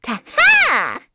Pansirone-Vox_Attack3_kr.wav